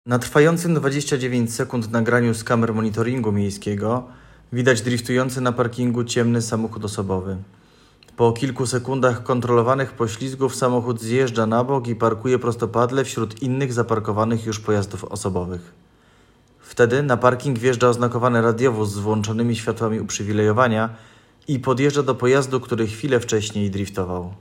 Nagranie audio audiodeskrypcja_do_filmu_-_drifty_na_Mostowej_w_Myslenicach.m4a